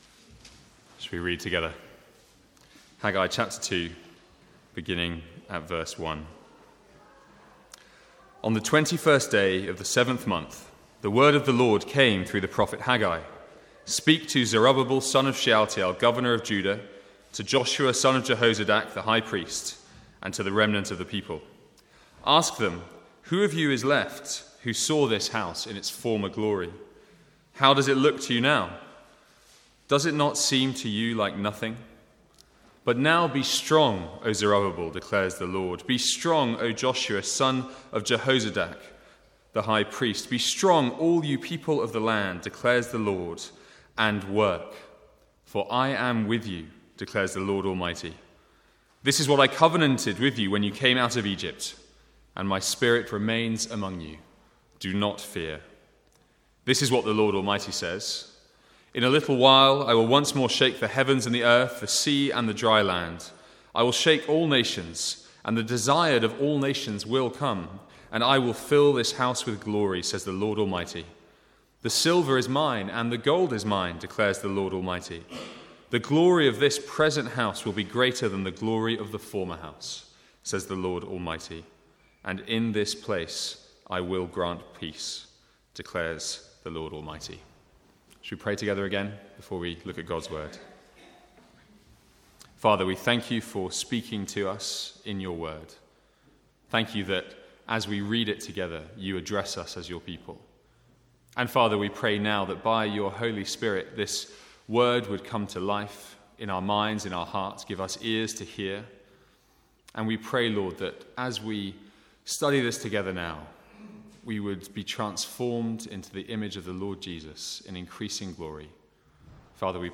From the Sunday morning series in Haggai.
Sermon Notes